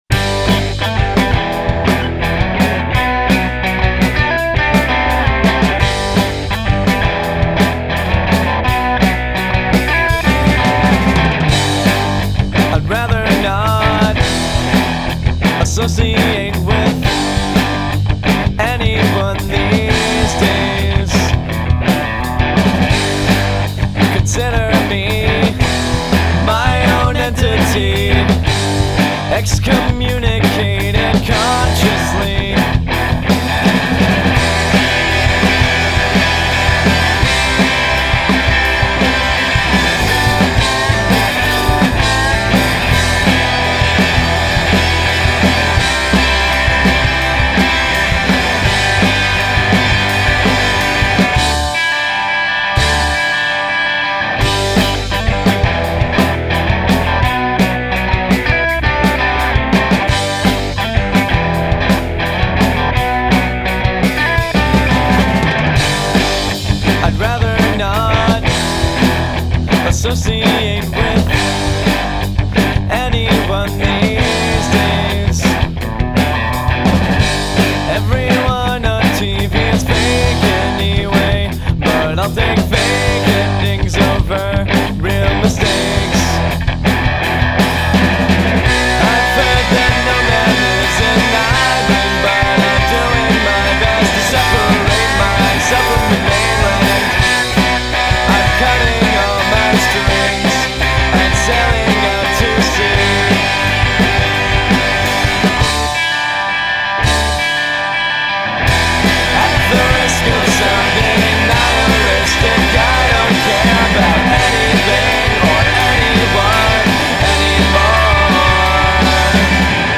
the saviors of jangly noodly punk rock.